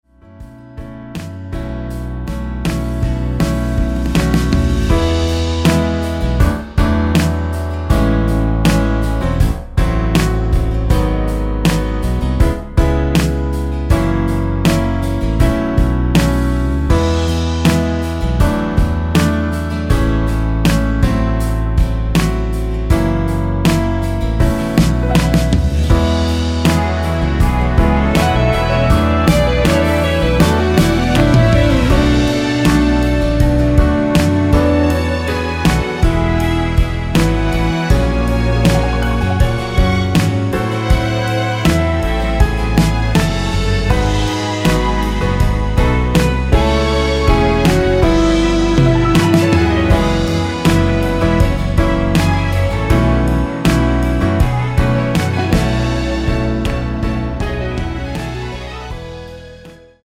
원키 2절 삭제 MR 입니다.(미리듣기및 본문의 가사 참조)
엔딩이 페이드 아웃이라 라이브 하시기 좋게 엔딩을 만들어 놓았습니다.
앞부분30초, 뒷부분30초씩 편집해서 올려 드리고 있습니다.